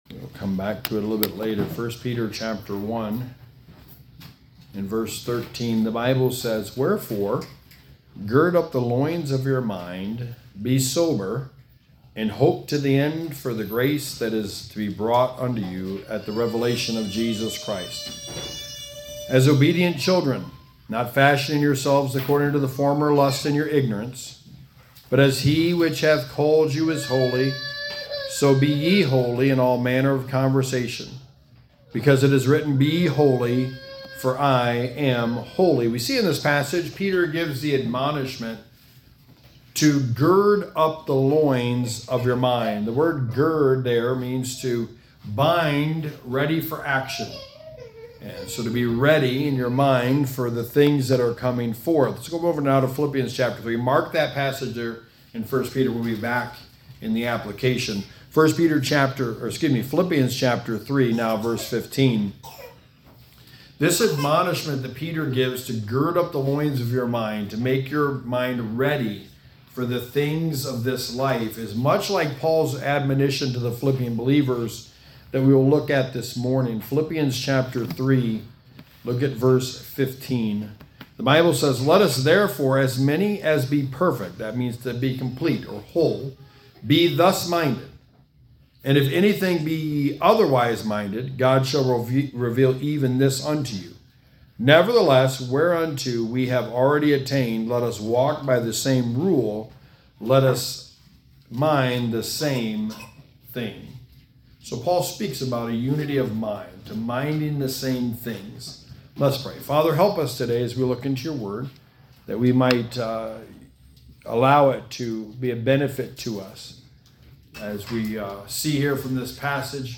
Sermon 22: The Book of Philippians: Thus Minded
Service Type: Sunday Morning